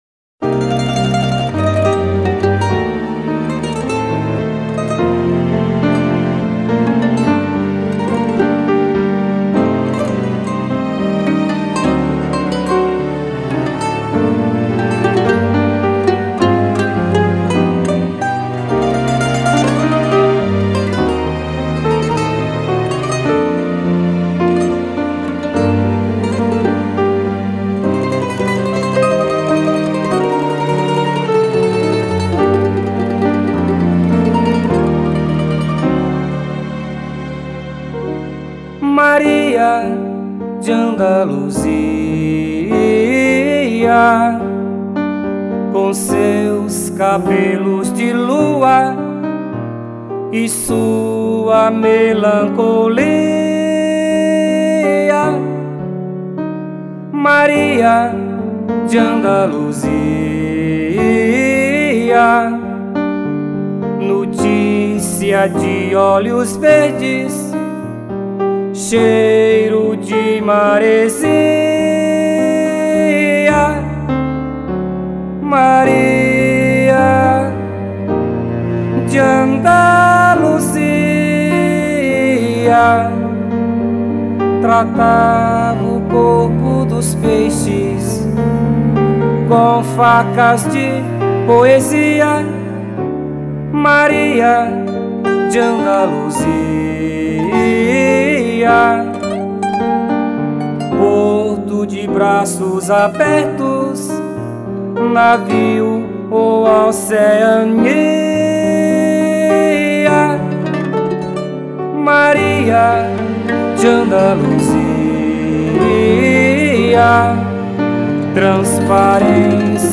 04:06:00   Modinha